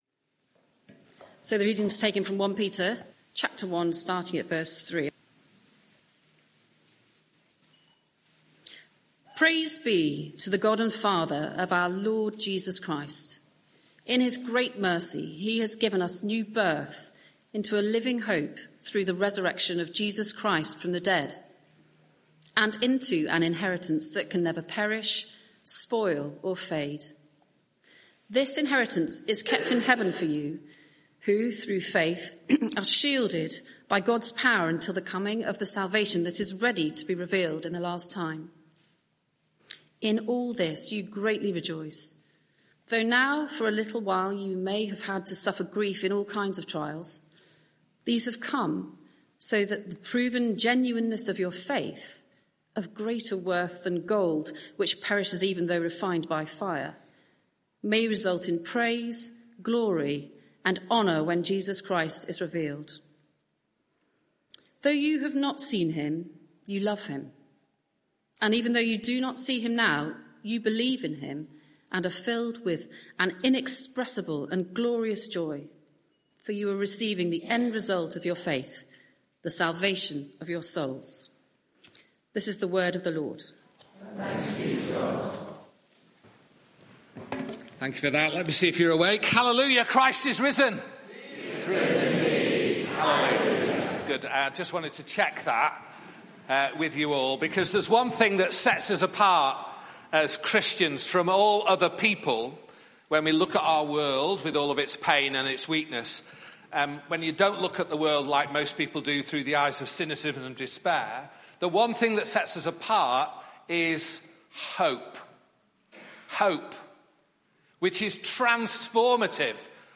Media for 6:30pm Service on Sun 20th Apr 2025 18:30 Speaker
Sermon (audio) Search the media library There are recordings here going back several years.